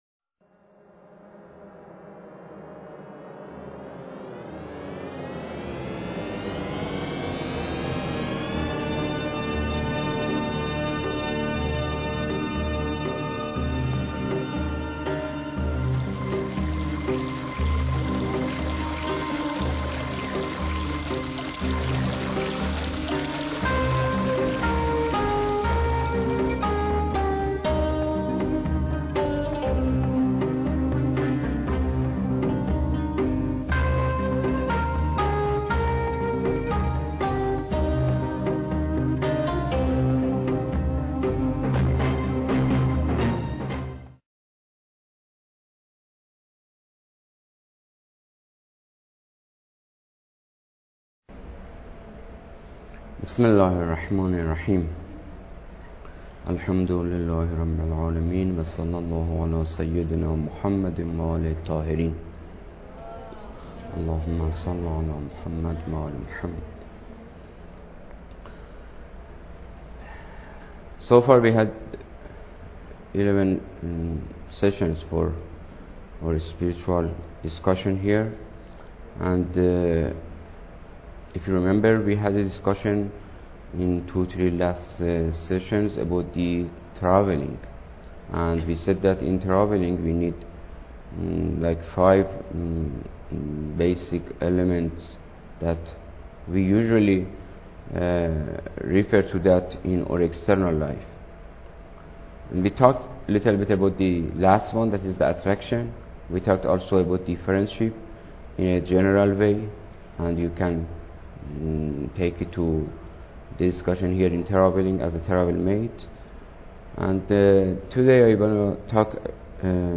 Lecture_12